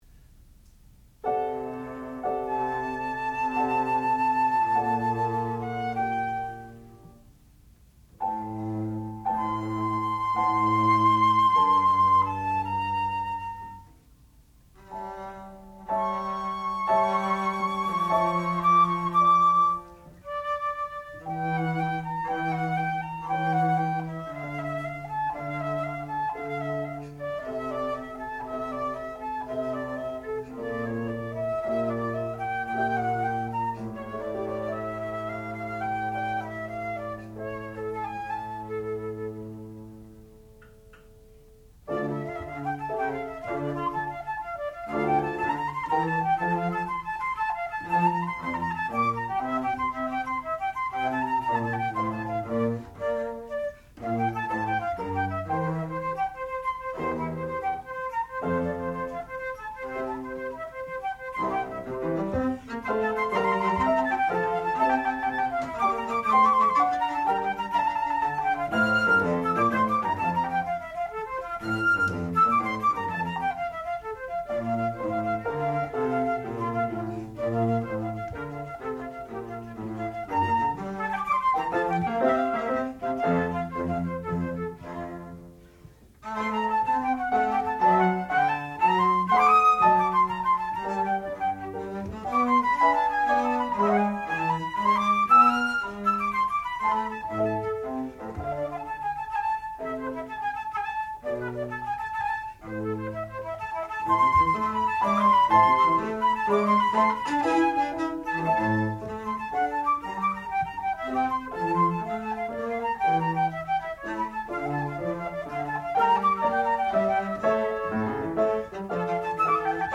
sound recording-musical
classical music
violoncello
piano
Qualifying Recital